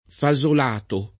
[ fa @ ol # to ]